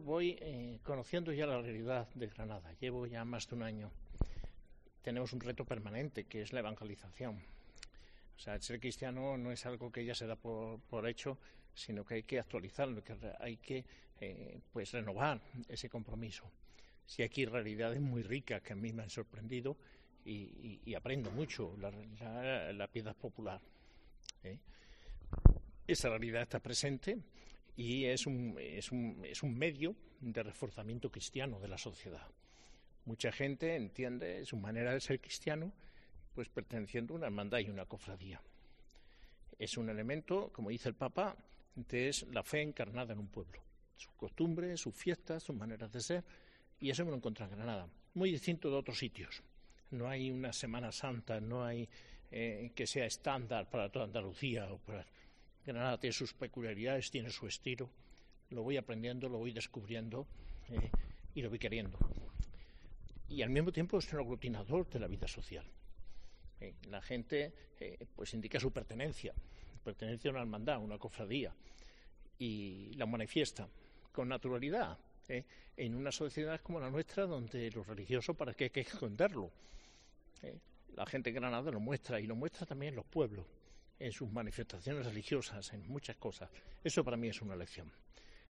El Arzobispo habla sobre las cofradías en su encuentro con los periodista con motivo de su patrón